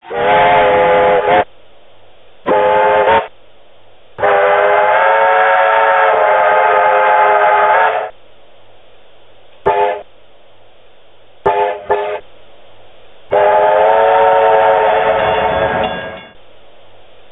Recorded Whistles for Live Steam Locomotives
Puffing Billy 5 Chime
whistles_na_5_chime.mp3